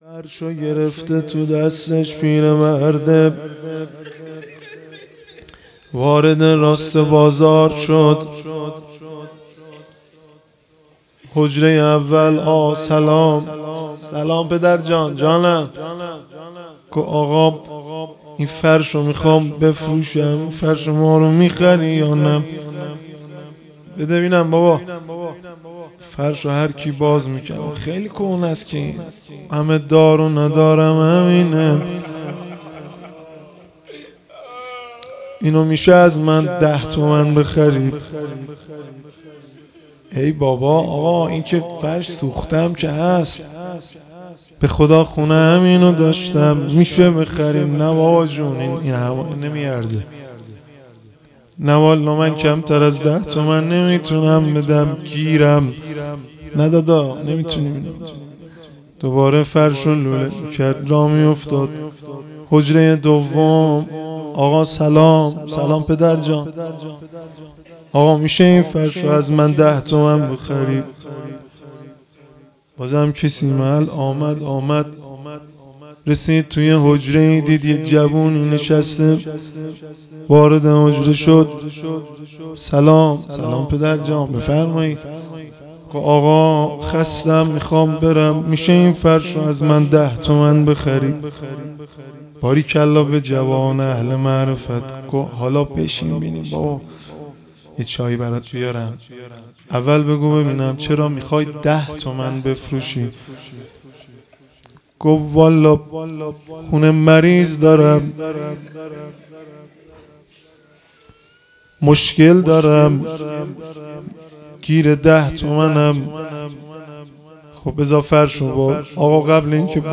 هیئت ثارالله زنجان